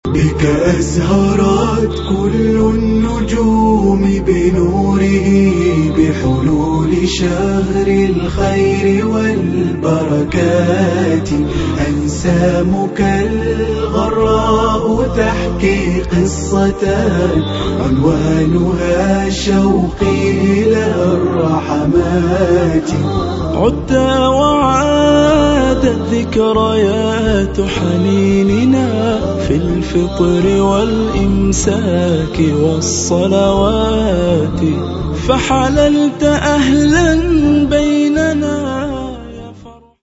أنشودة
اناشيد